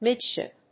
アクセント・音節míd・shìp